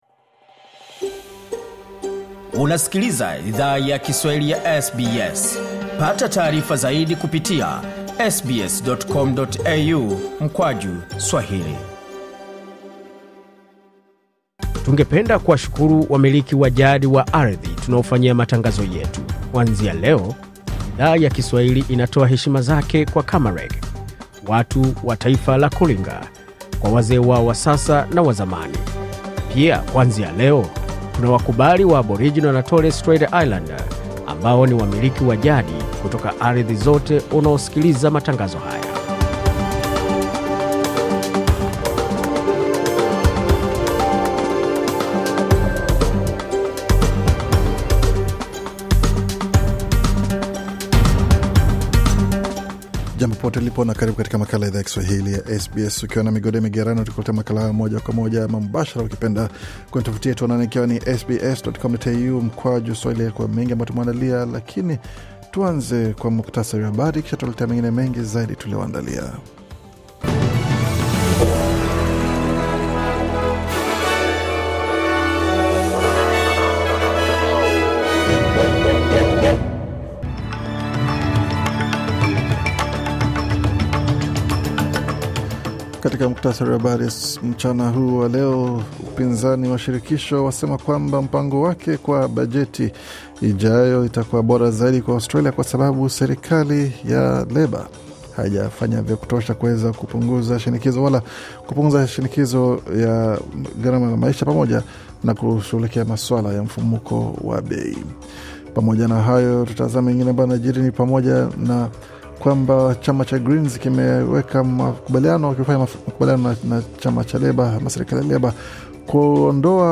Taarifa ya Habari 17 Mei 2024